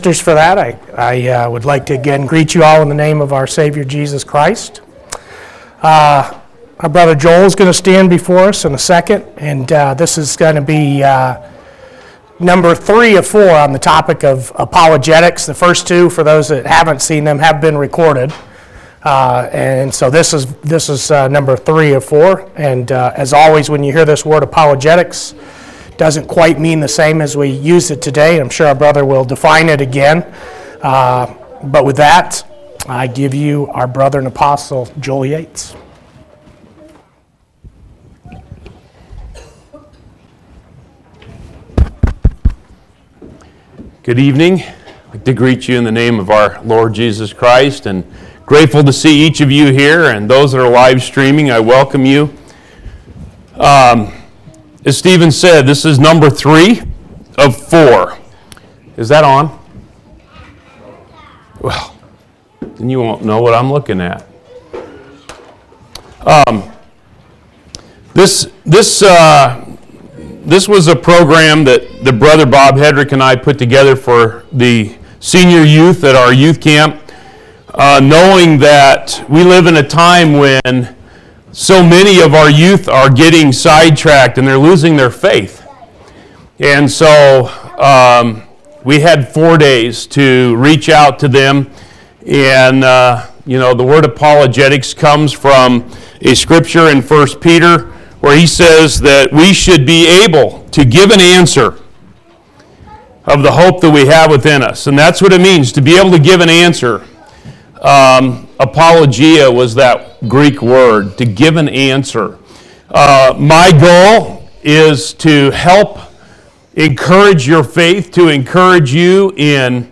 11/18/2018 Location: Phoenix Local Event